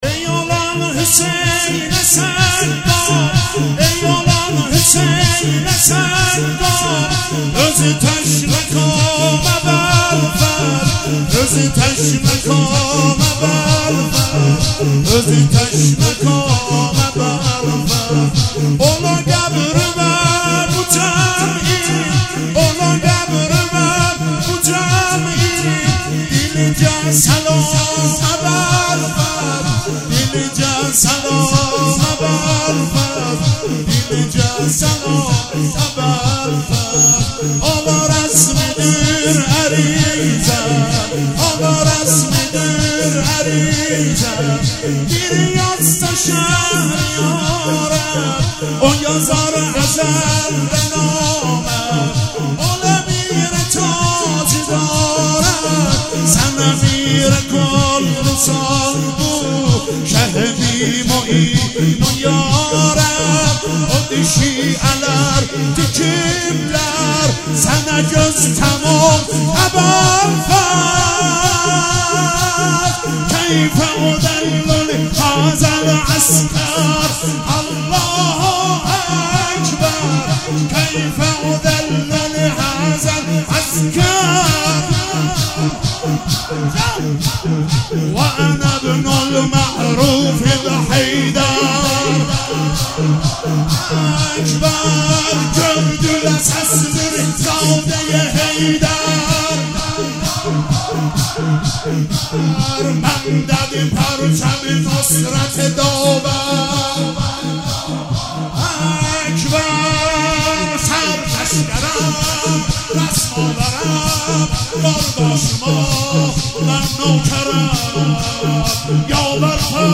مداحی آذری